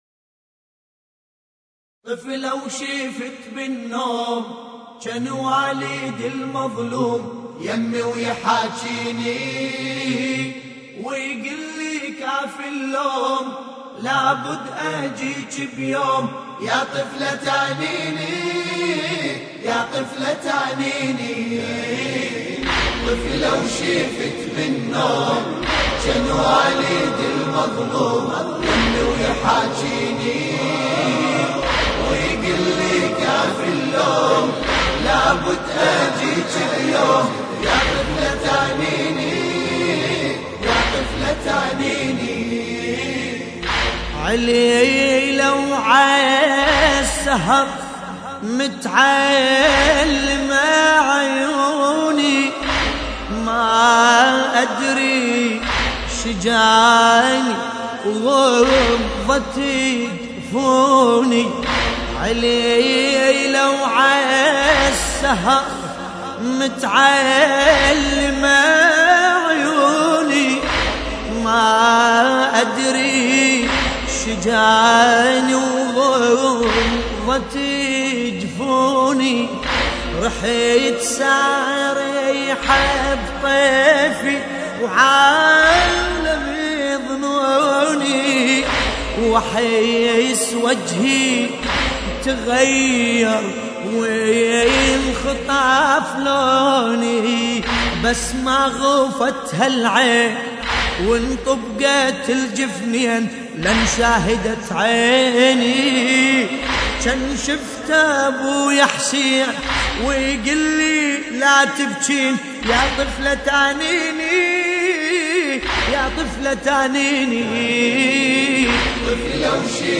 تحميل : طفلة وشفت بالنوم جن والدي المظلوم يمي ويحاكيني ويقلي كافي اللوم / الرادود باسم الكربلائي / اللطميات الحسينية / موقع يا حسين